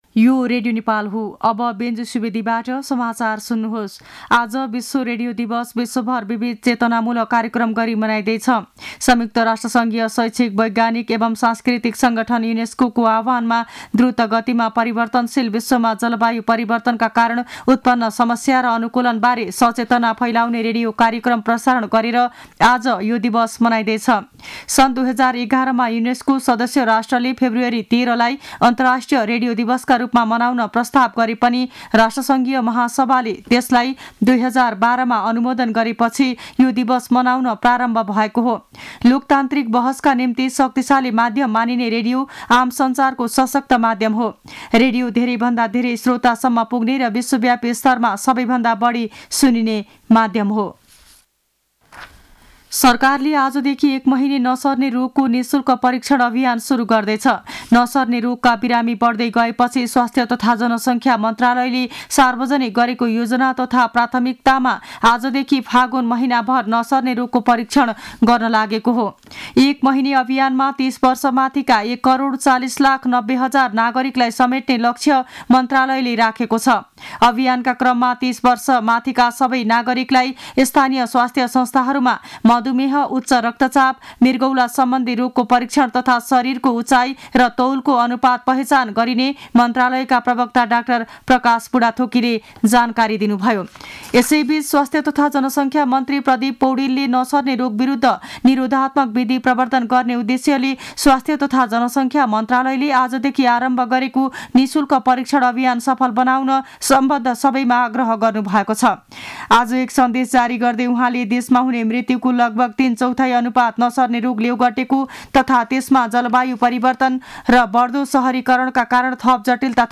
दिउँसो १ बजेको नेपाली समाचार : २ फागुन , २०८१